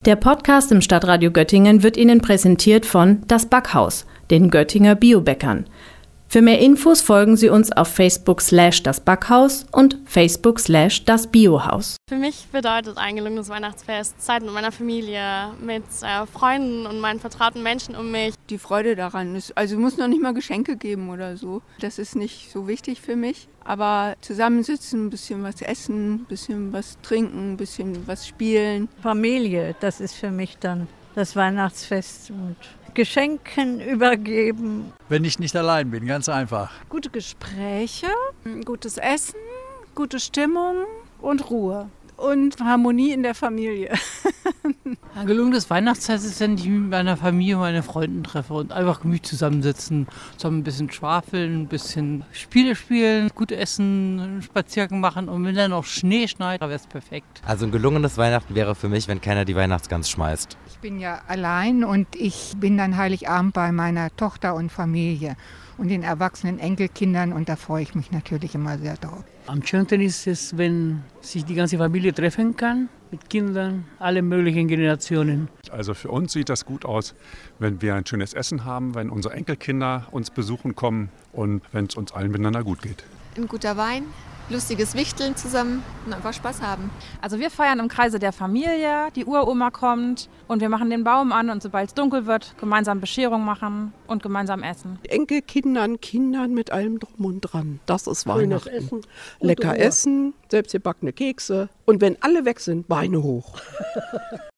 war für Sie in Göttingen unterwegs und hat sich umgehört